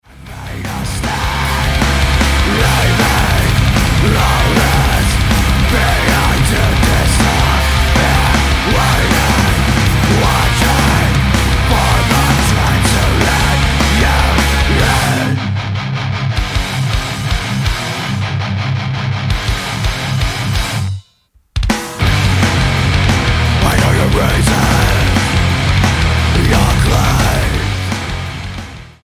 STYLE: Hard Music